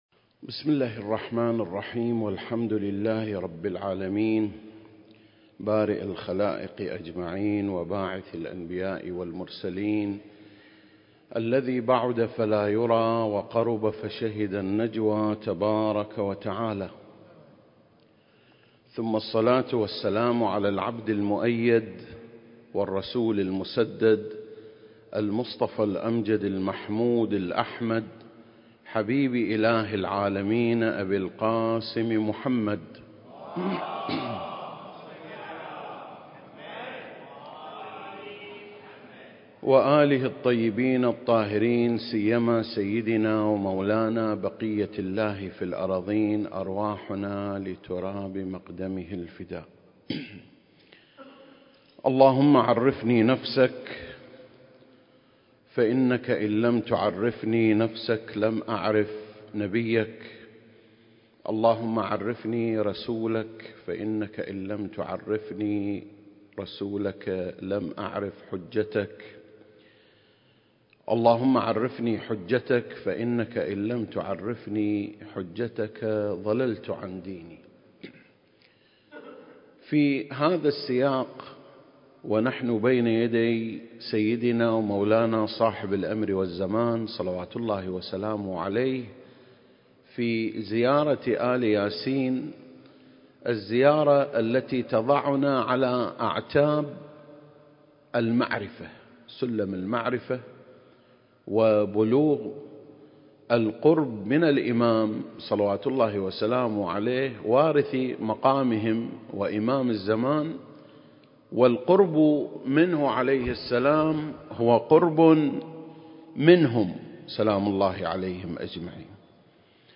سلسلة: شرح زيارة آل ياسين (14) - قصة التوبيخ (2) المكان: مسجد مقامس - الكويت التاريخ: 2021